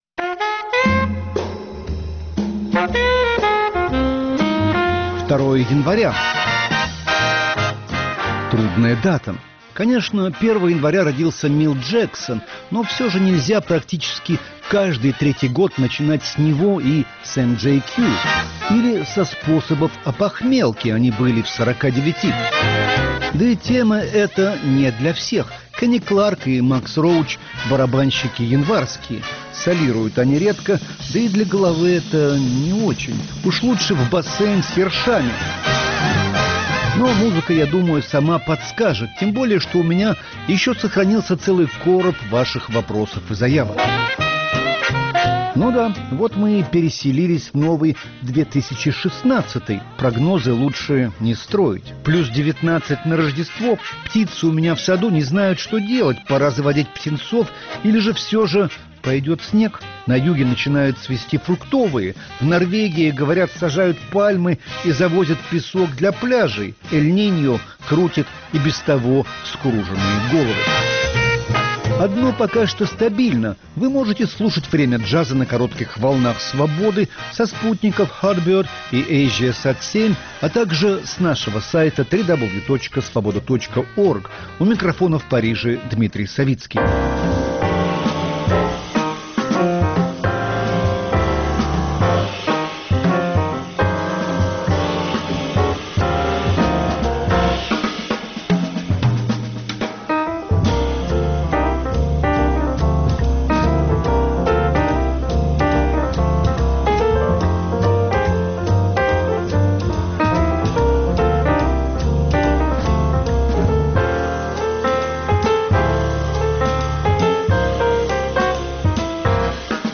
Начнем просто с джаза и с одного из ваших вопросов, которых у меня чуть больше, чем квитанций за парковку в запрещенном месте. Тема – cool.